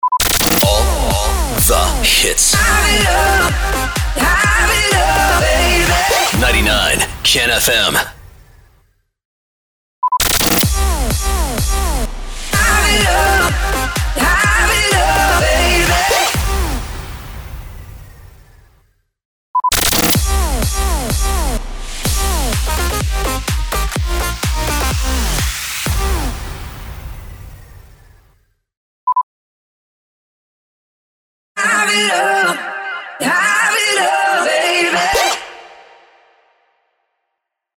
425 – SWEEPER – HAVE IT ALL